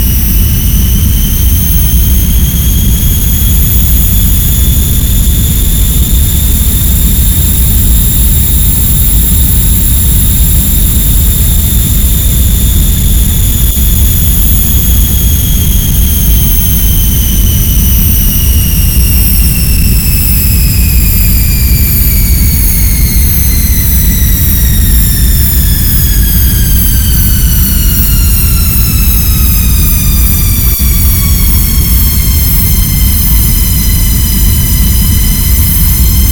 After a while it gets a little monotone.
experimental noise